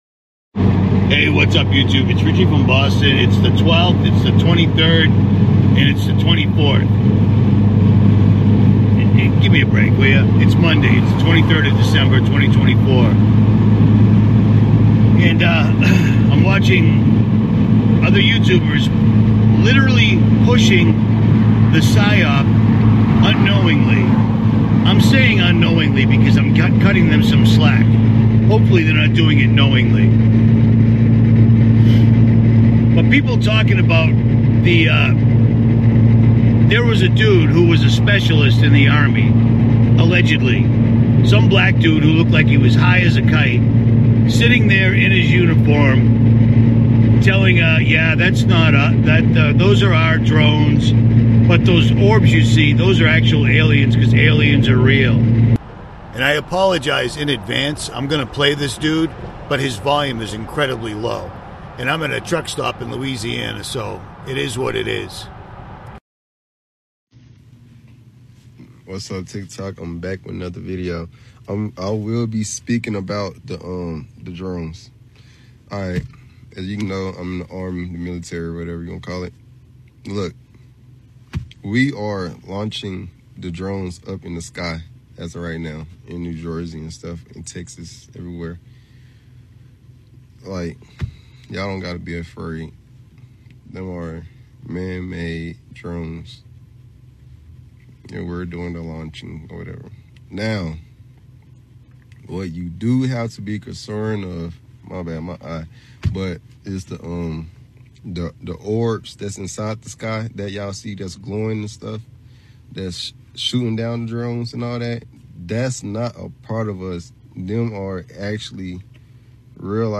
And I apologize in advance, I’m gonna play this dude, but his volume is incredibly low. And I’m at a truck stop in Louisiana, so it is what it is.